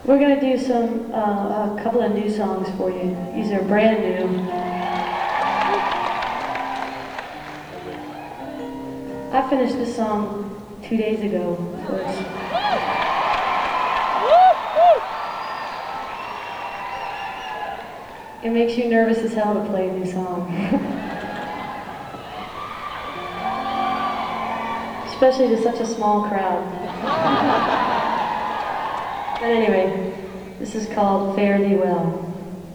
lifeblood: bootlegs: 1991-09-01: seattle center coliseum - seattle, washington (alternate recording - 24 bit version)
(acoustic duo)
11. talking with the crowd (0:32)